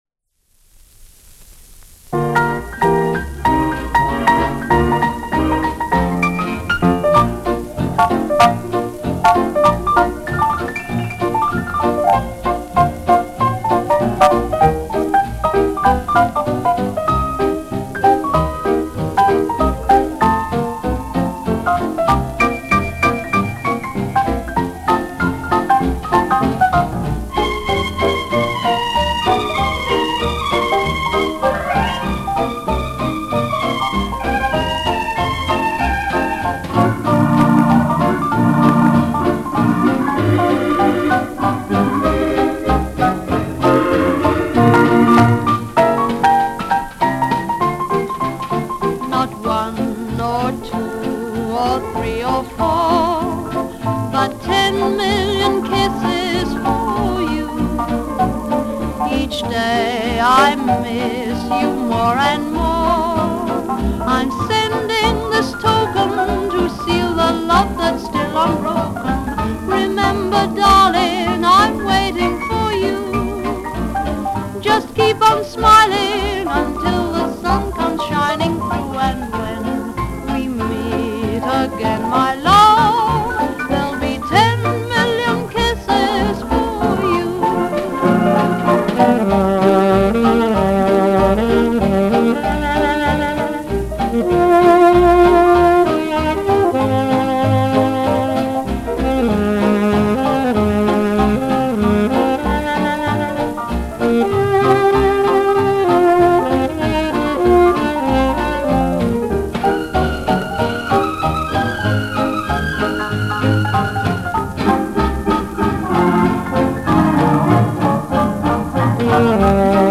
350Hz Turnover